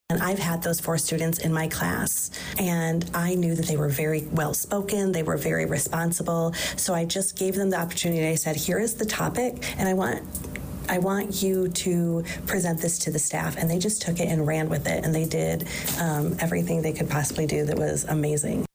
The Danville District 118 Board meeting Wednesday evening (April 23rd) may have been focused on bus issues, along with superintendent thoughts in the backs of many minds.